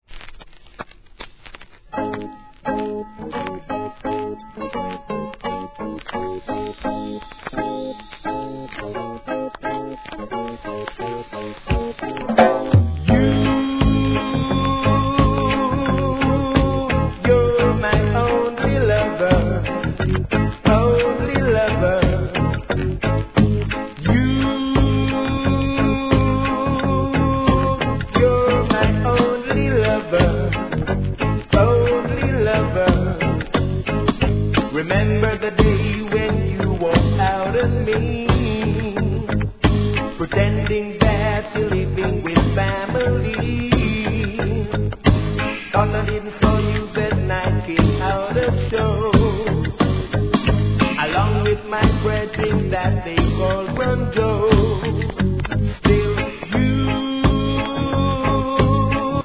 REGGAE
ベースの渋い好RHYTHMです!